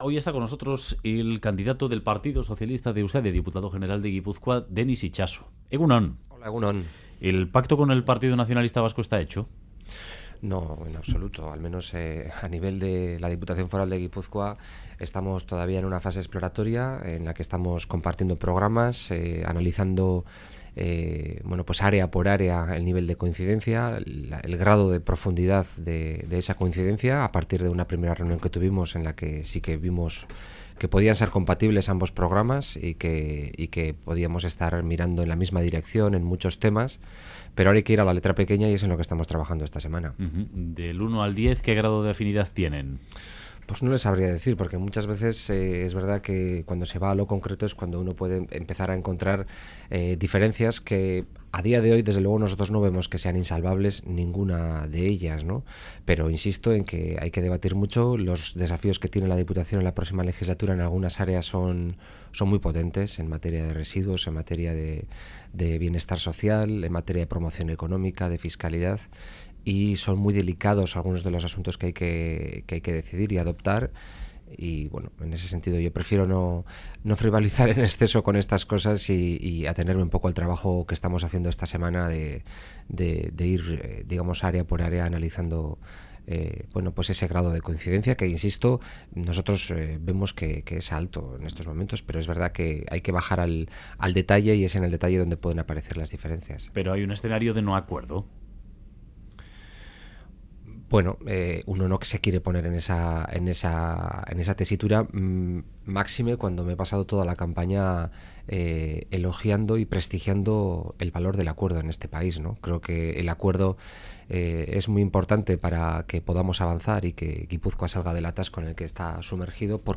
Radio Euskadi BOULEVARD Denis Itxaso: 'El pacto con el PNV en absoluto está hecho' Última actualización: 08/06/2015 09:28 (UTC+2) En entrevista al Boulevard de Radio Euskadi, el candidato del PSE-EE a Diputado General de Gipuzkoa, Denis Itxaso, ha afirmado que el pacto con el PNV "en absoluto está hecho", y ha subrayado que a nivel de Gipuzkoa los contactos están en fase exploratoria. Ha añadido, no obstante, que no quiere ponerse en la tesitura de un no-acuerdo con el PNV, aunque considera que para pensar a un gobierno de coalición habría que llegar a un nivel de entendimiento muy alto.